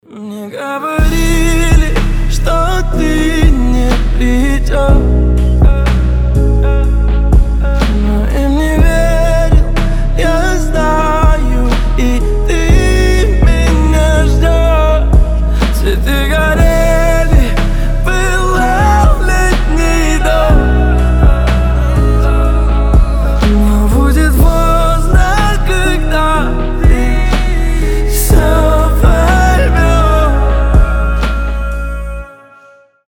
• Качество: 320, Stereo
красивый мужской голос